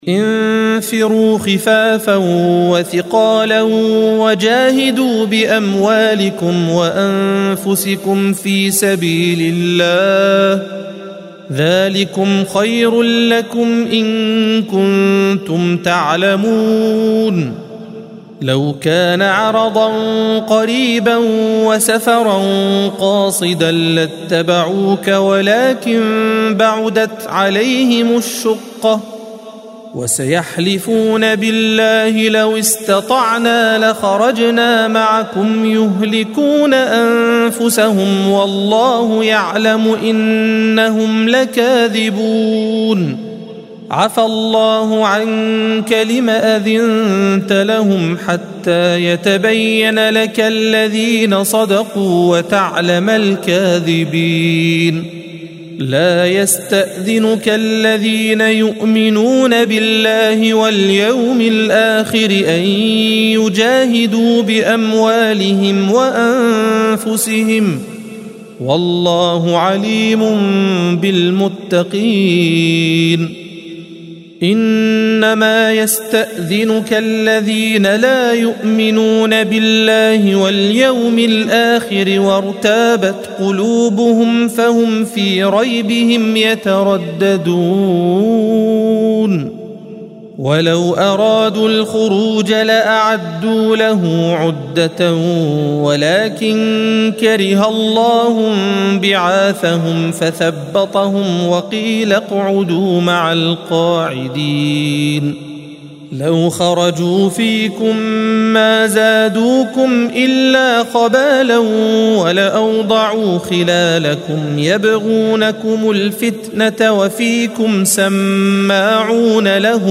الصفحة 194 - القارئ